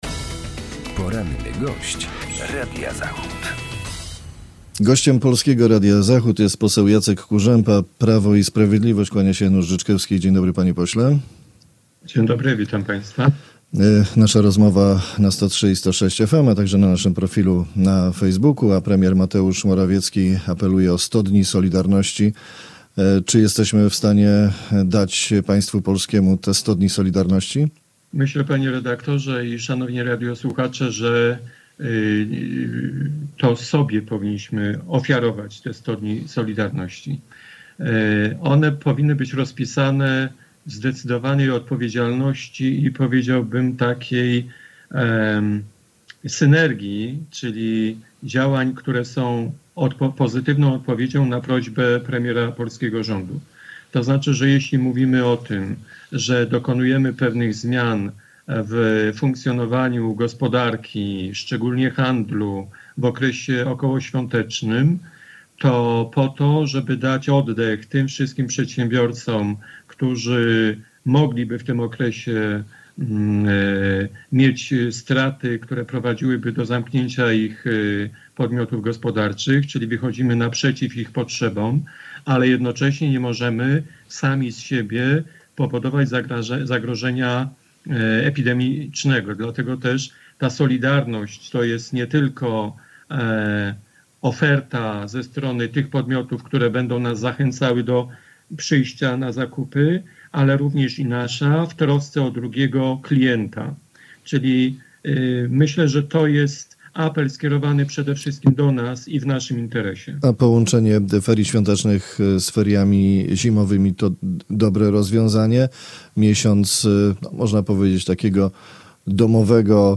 Rozmowa uległa przerwaniu w przyczyn technicznych.